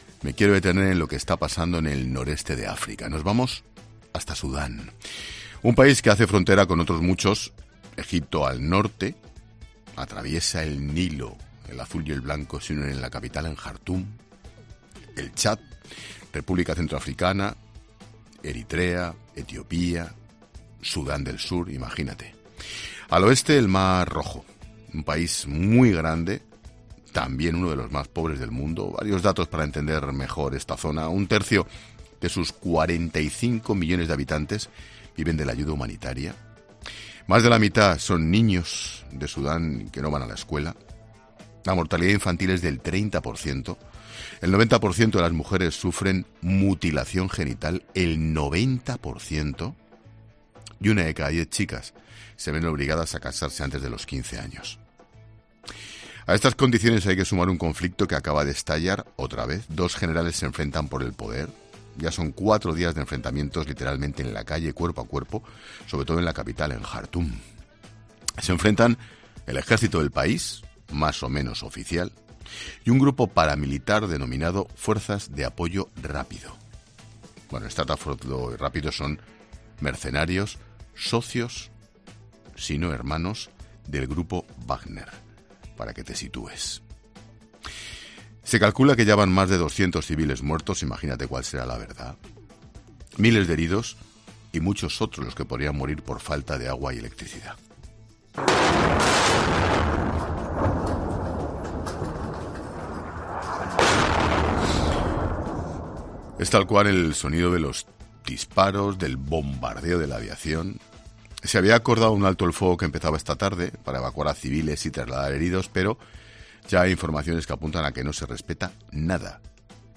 El nuncio apostólico en Sudán, donde viven cerca de un millón de católicos, narra en La Linterna la situación que están viviendo en el país desde...